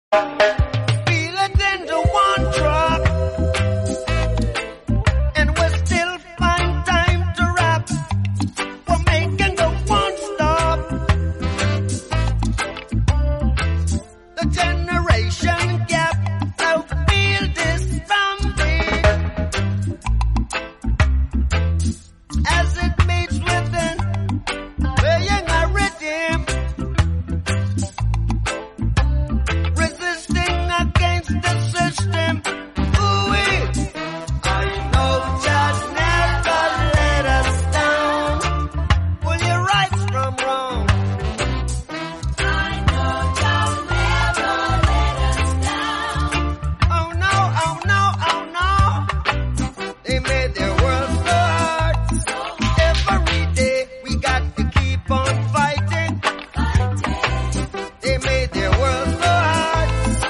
A Reggae Revolution